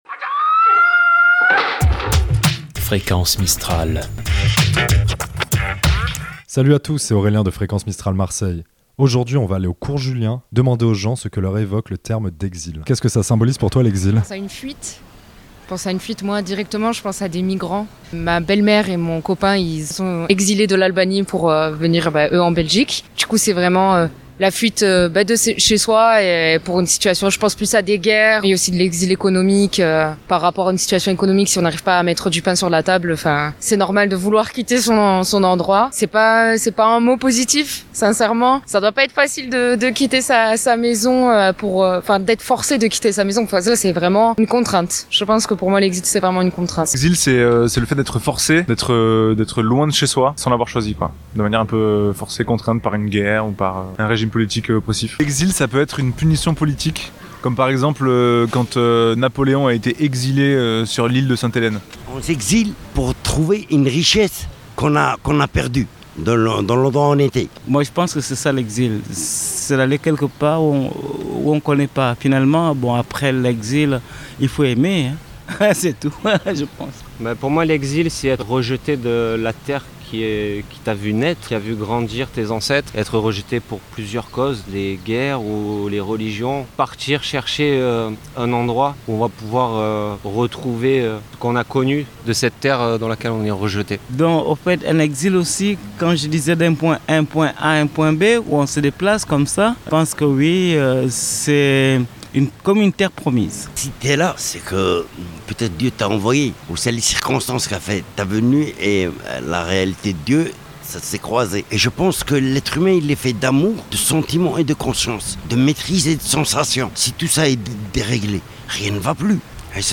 Micro-trottoir- C'est quoi l'exil selon toi?
micro trottoir exil.mp3 (4.7 Mo)
Jeudi 12 Septembre 2024 Aujourd'hui, nous allons au Cours Julien demander aux personnes ce que symbolise le terme de l'exil selon eux.